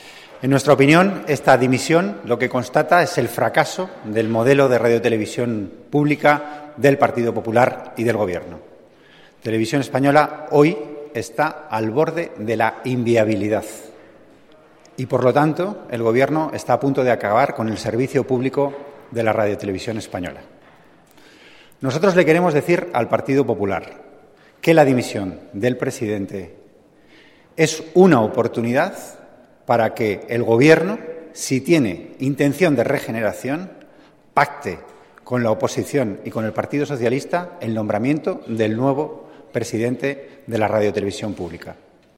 Declaraciones de Antonio Hernando tras la dimisión del presidente de RTVE 25-09-14